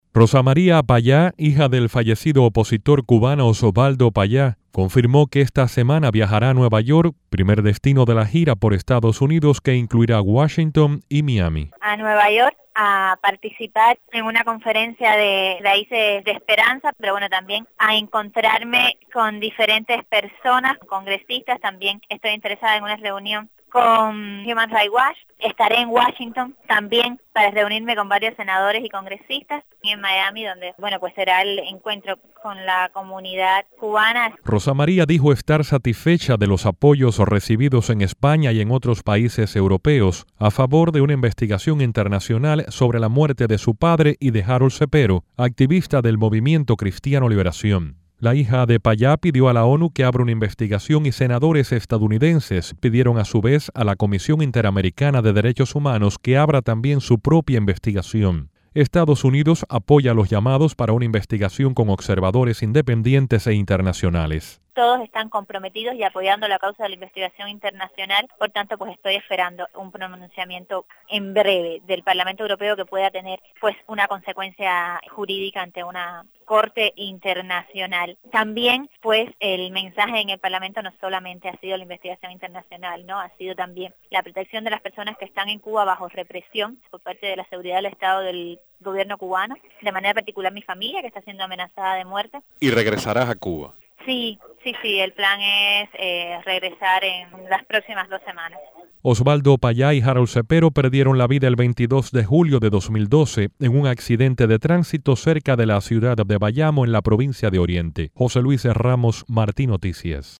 Rosa María Payá, entrevistada